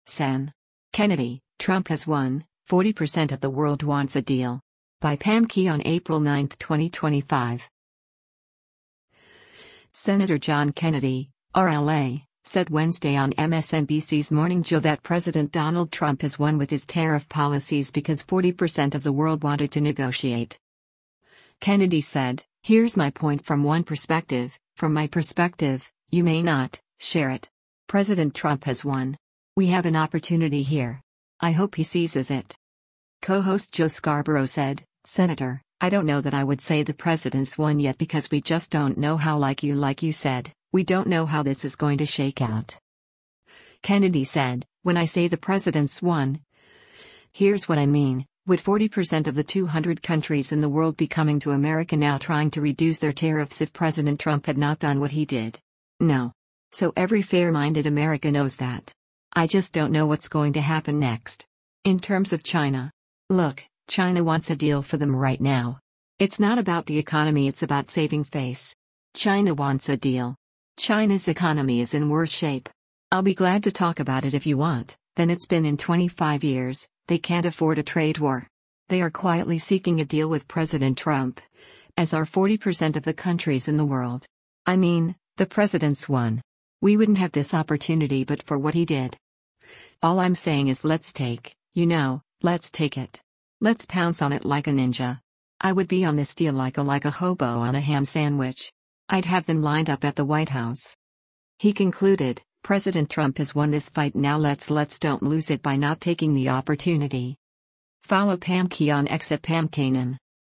Senator John Kennedy (R-LA) said Wednesday on MSNBC’s “Morning Joe” that President Donald Trump “has won” with his tariff policies because 40% of the world wanted to negotiate.